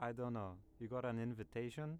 Voice Lines
I dont know you got an invitation.wav